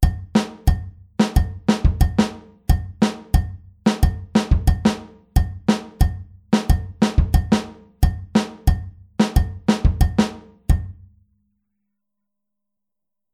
Rechte Hand auf Kopfbecken (bell/head) oder Kuhglocke (cowbell)
Bei der 4tel-Variante spielt die rechte Hand nicht mehr auf dem HiHat sondern wegen des Punches auf dem Kopfbecken (bell/head aber nicht mit der Kuppe) oder der Kuhglocke (cowbell).
Groove16-4tel.mp3